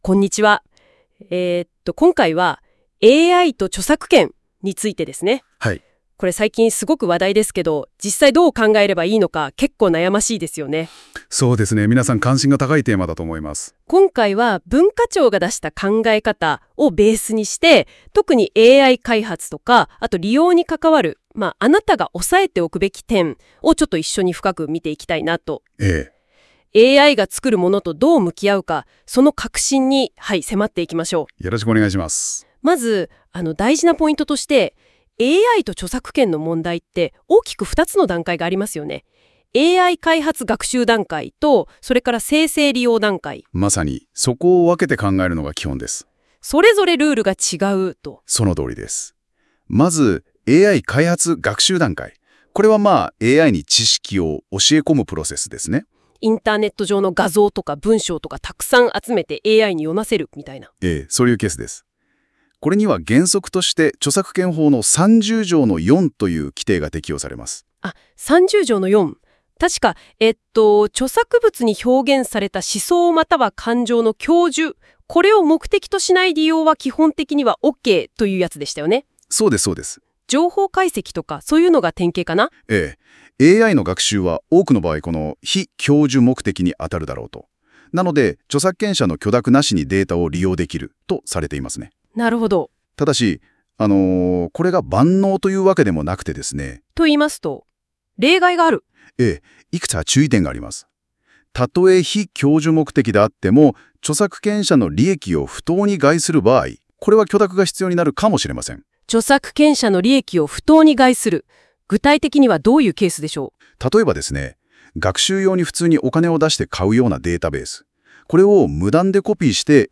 その内容をラジオ風に
喋ってくれるという音声生成機能
NotebookLMに音声を作ってもらうと・・・